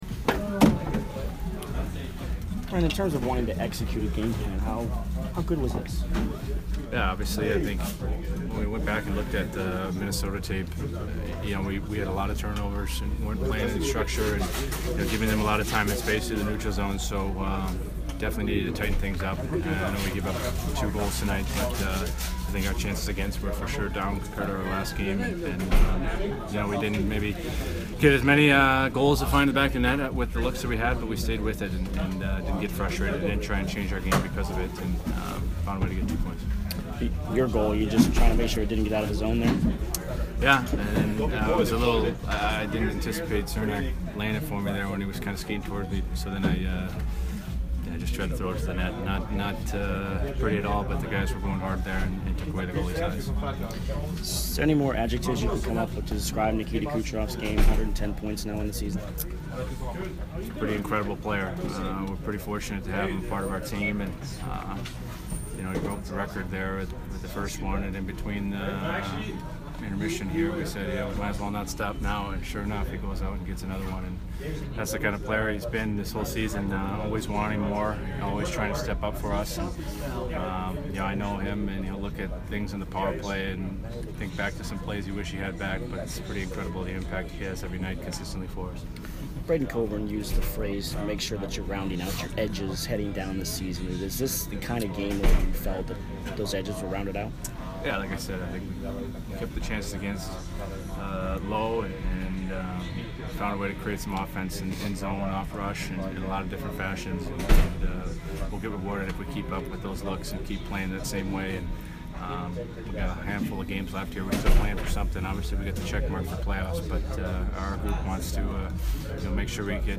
Ryan McDonagh post-game 3/9